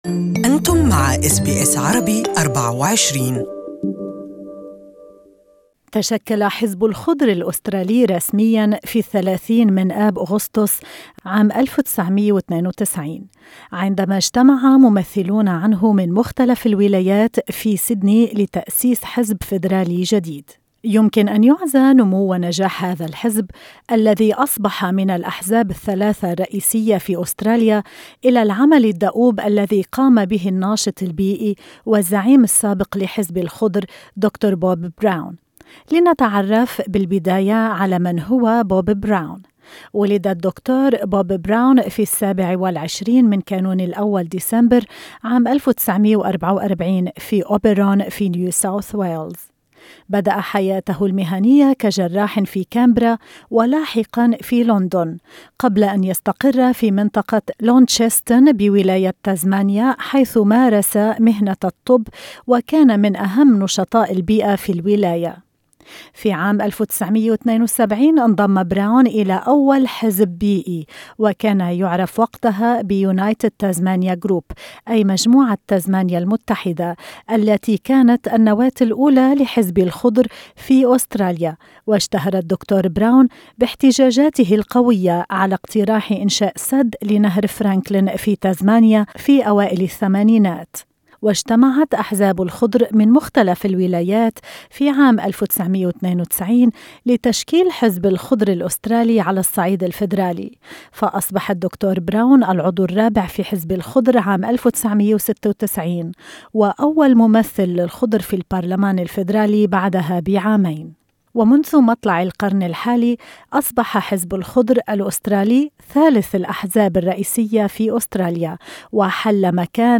تعرف على حزب الخضر الأسترالي في هذا التقرير.